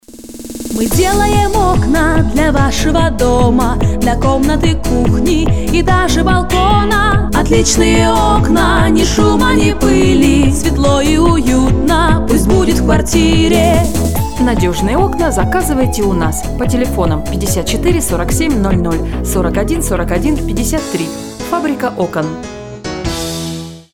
радио-ролик "Фабрика окон" Категория: Аудио/видео монтаж